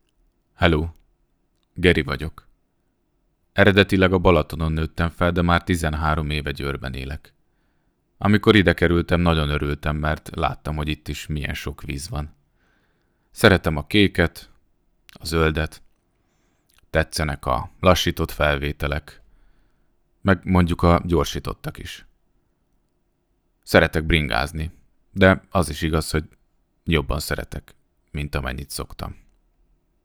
Egyedi kérések alapján készítjük el számodra a hanganyagot, RØDE stúdió mikrofonnal.
színész-drámatanár, drámapedagógus